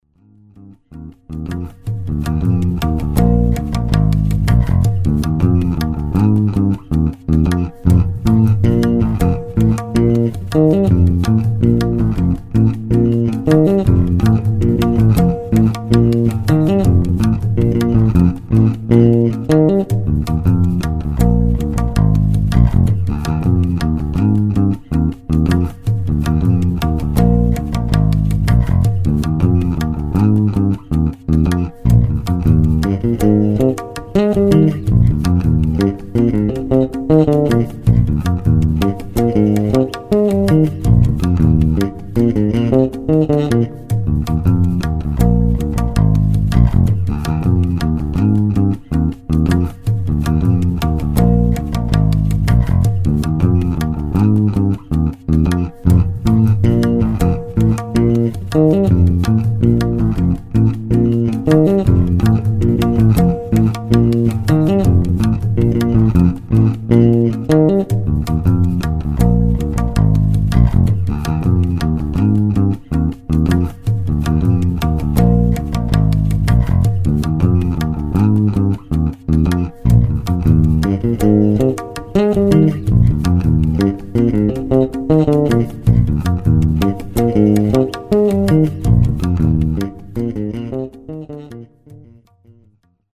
je me demandais si vous aviez pas des ptits plans créoles à partager? un p'tit playback perso à la basse y'aurais bien un passage un tantinet "créole" dedans non ? https